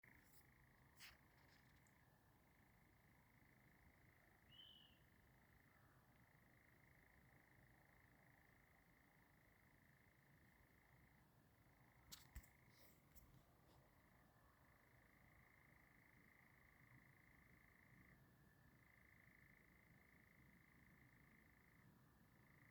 European Nightjar, Caprimulgus europaeus
StatusSinging male in breeding season